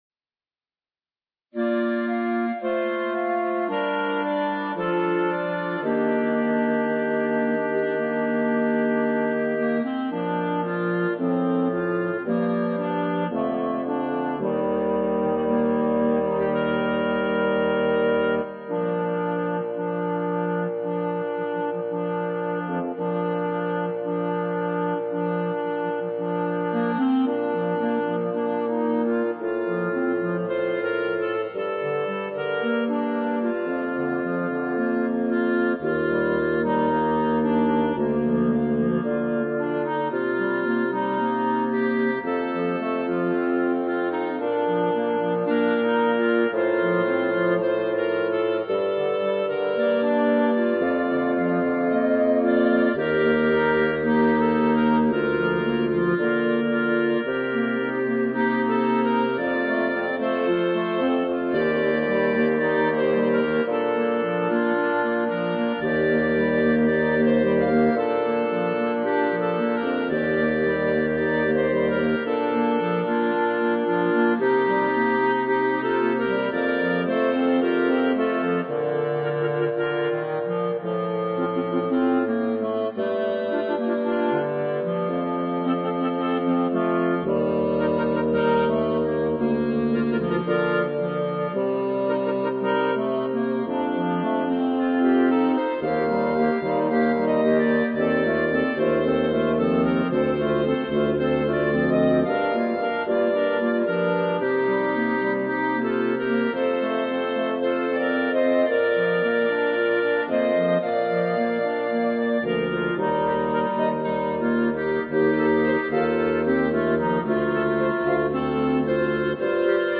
B♭ Clarinet 1 B♭ Clarinet 2 B♭ Clarinet 3 Bass Clarinet
单簧管四重奏
它的旋律感人至深，令人心潮澎湃。